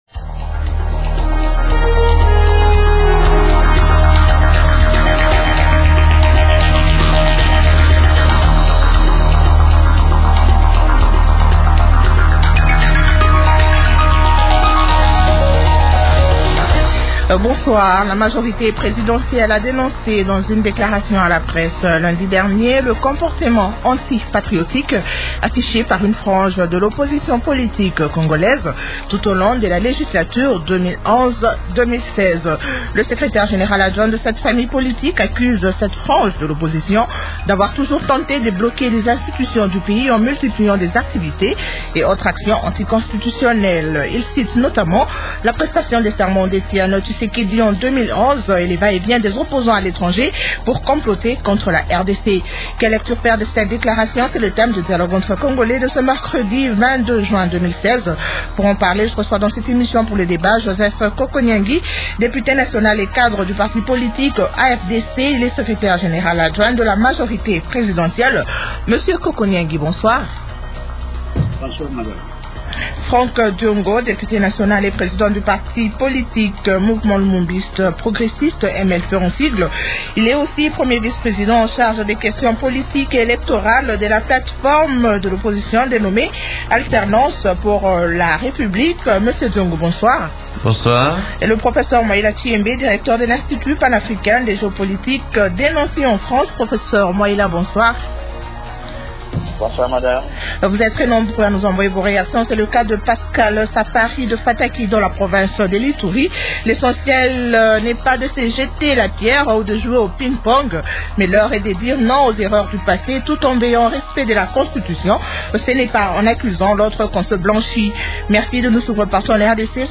Participent au débat de ce soir : -Joseph Kokonyangi, Député national et cadre du parti politique Afdc.
-Franck Diongo, Député national et président du parti politique Mouvement Lumumbiste Progressiste (Mlp).